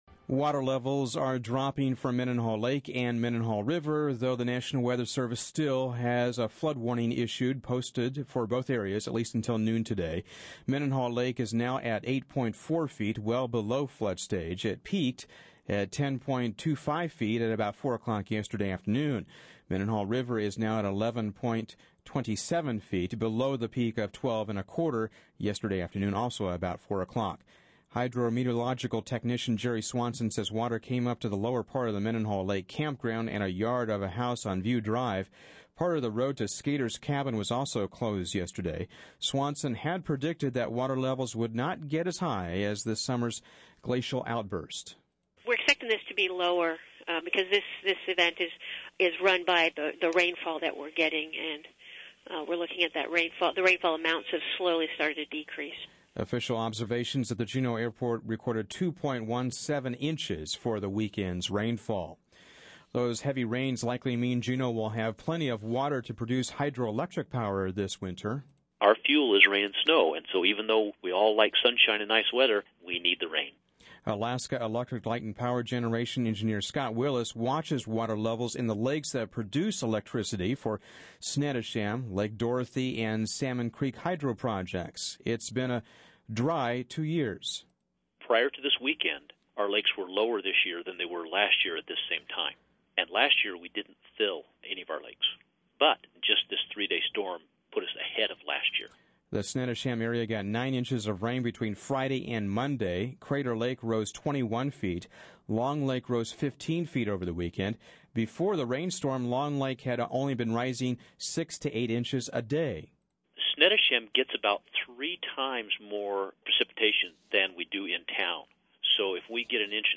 Thursday Newscast